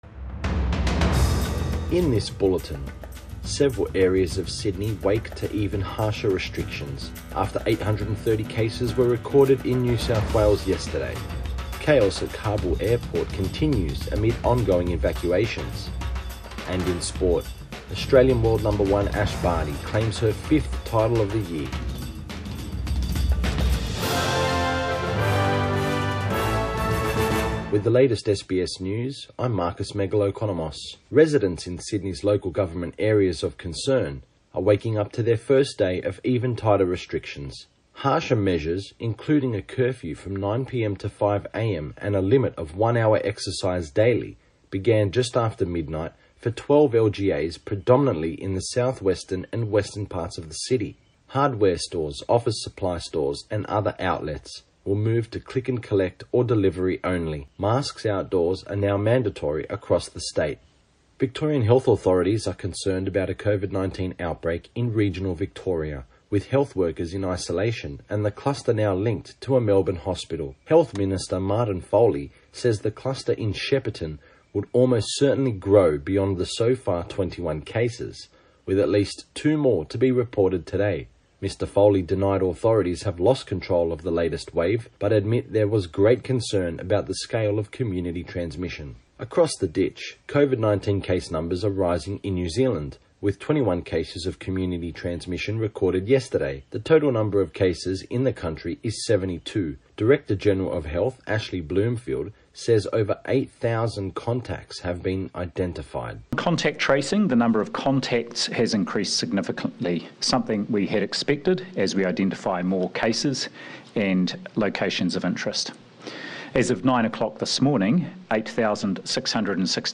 AM bulletin 23 August 2021